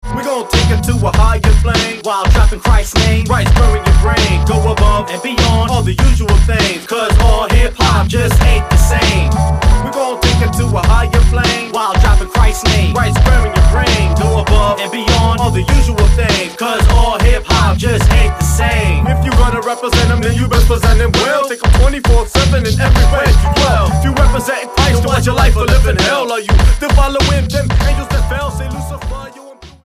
STYLE: Hip-Hop